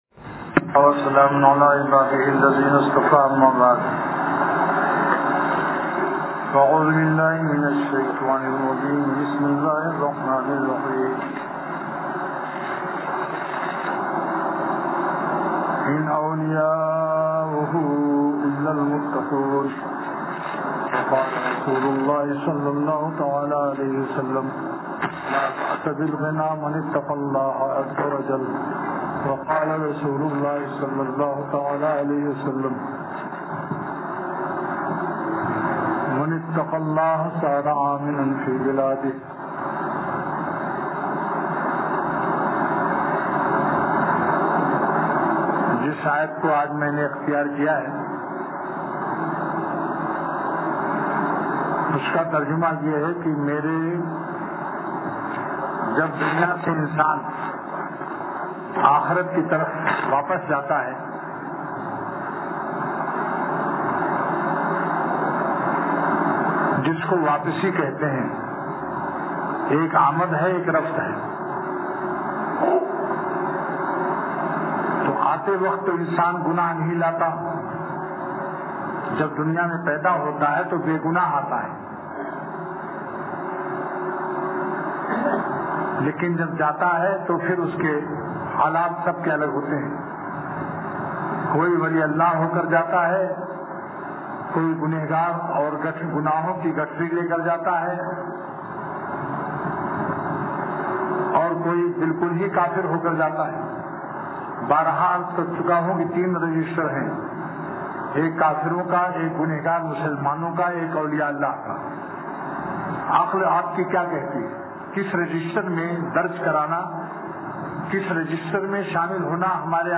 بیان حضرت والا رحمتہ اللہ علیہ – مجالس ابرار – دنیا کی حقیقت – نشر الطیب فی ذکر النبی الحبیب صلی اللہ علیہ وسلم